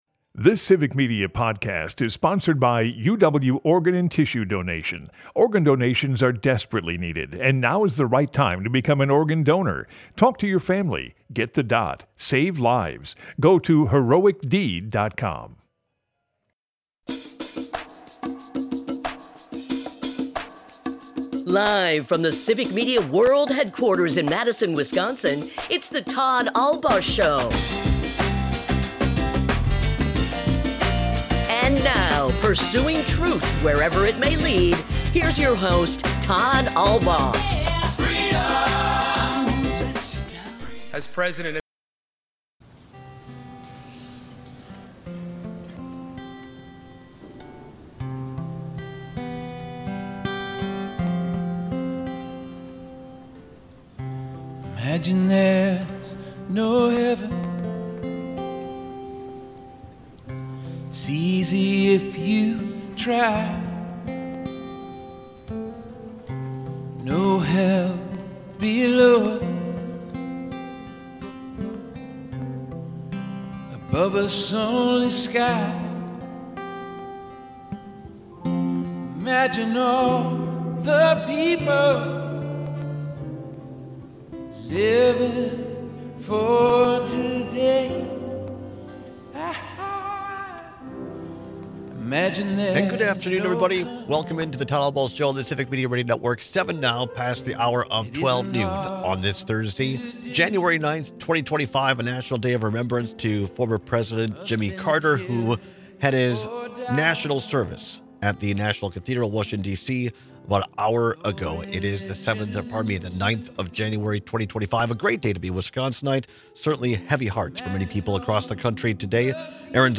Broadcasts live 12 - 2p across Wisconsin.
We also take some calls on the legacy of President Carter.